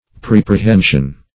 Search Result for " preapprehension" : The Collaborative International Dictionary of English v.0.48: Preapprehension \Pre*ap`pre*hen"sion\, n. An apprehension or opinion formed before examination or knowledge.